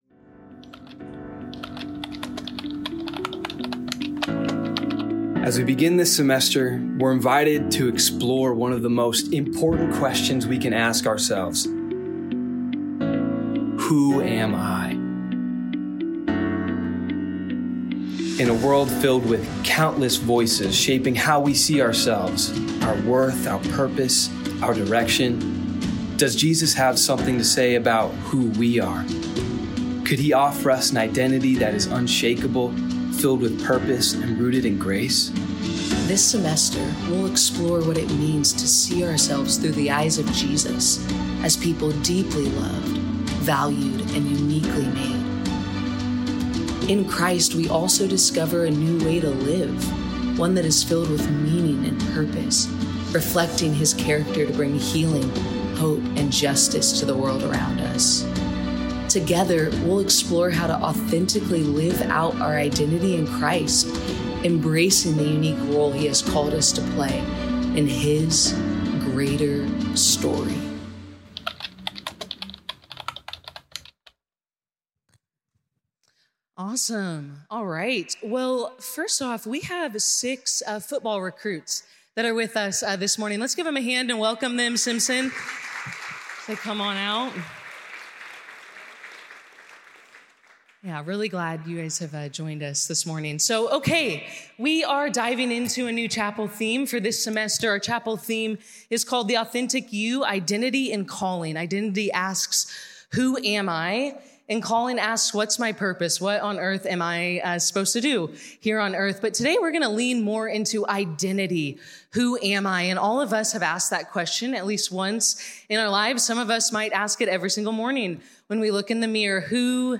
This talk was given in chapel on Wednesday, January 22nd, 2024 God Bless you.